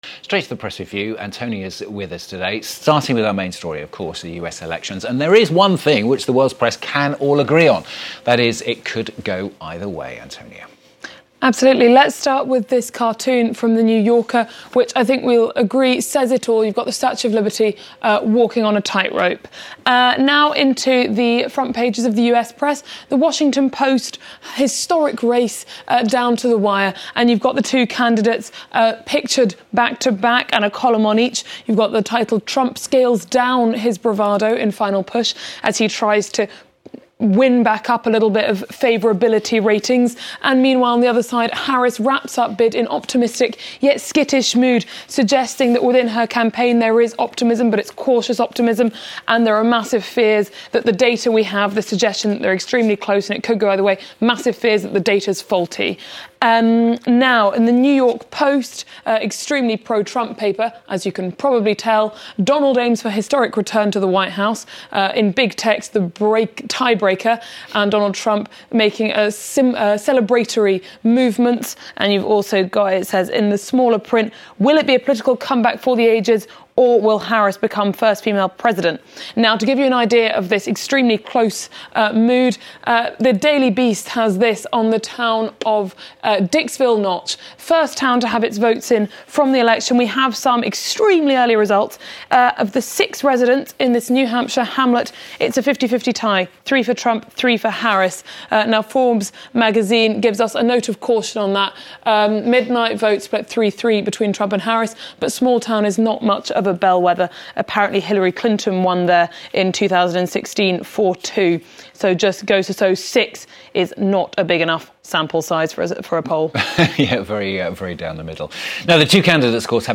PRESS REVIEW – Tuesday, November 5: We take a look at the US front pages ahead of the country's landmark presidential vote. Also: what, if any, difference will the election result make to the ongoing war in the Middle East?